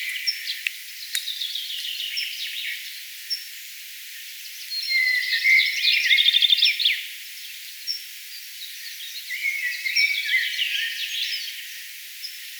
metsäkirvisen pieniä huomioääniä
ilm_metsakirvislinnun_tuollaisia_aania.mp3